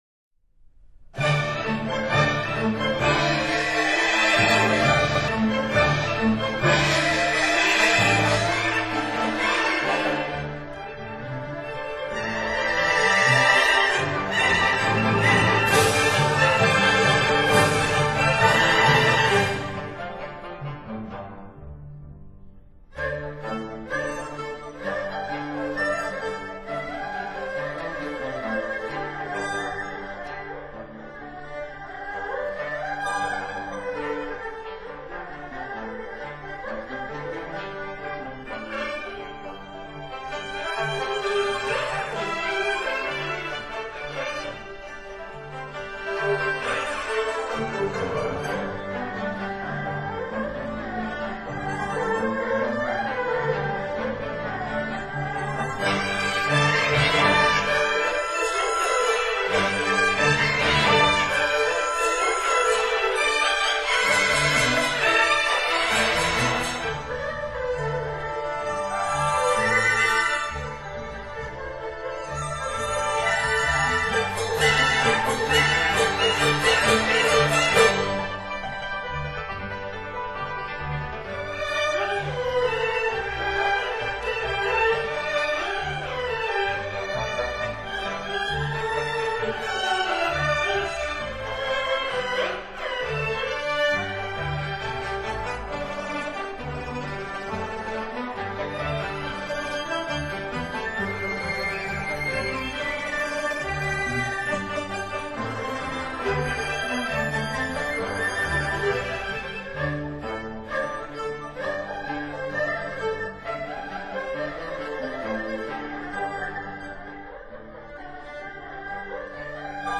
中国民族管弦乐及小品
作者以现代作曲技巧，配合中乐的特殊音色组合，描绘出三幅以声响构图的山水画。
管子